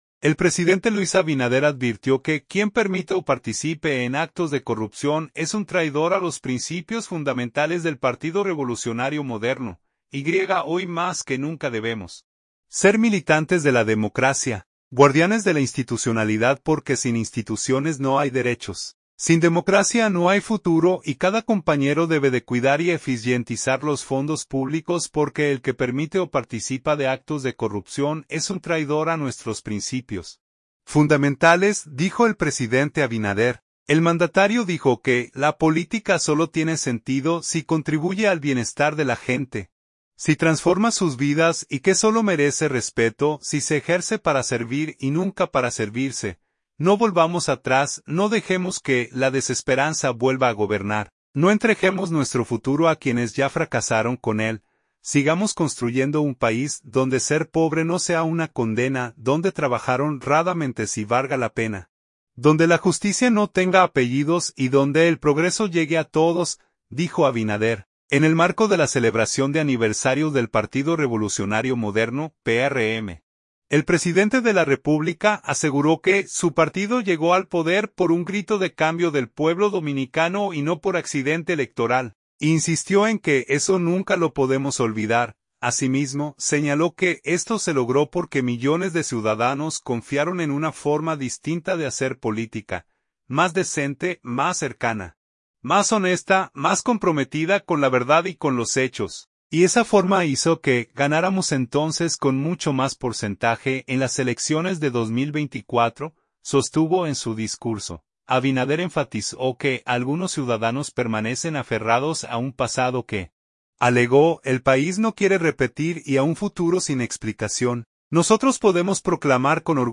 En el marco de la celebración de aniversario del Partido Revolucionario Moderno (PRM), el presidente de la República aseguró que su partido llegó al poder "por un grito de cambio del pueblo dominicano" y no por accidente electoral, e insistió en que “eso nunca lo podemos olvidar”.